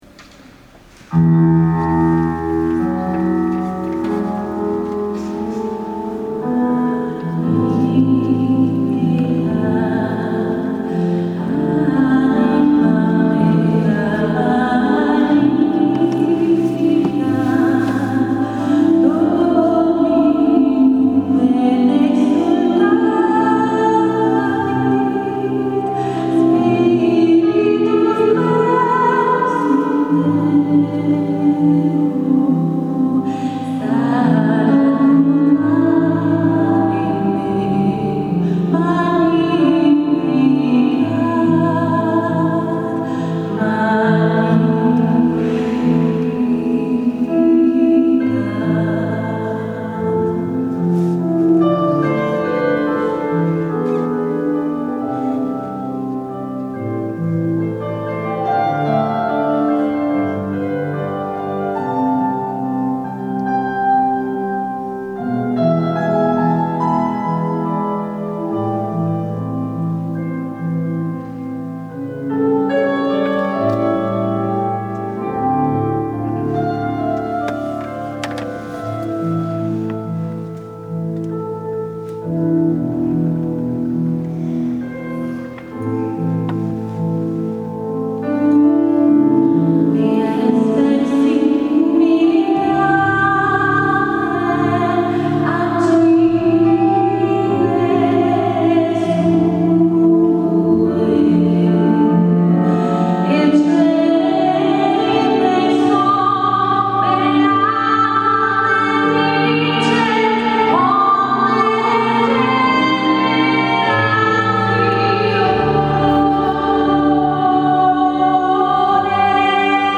Sabato 07 ottobre 2017 la corale ha animato la S. Messa in occasione della festività della Madonna del Rosario.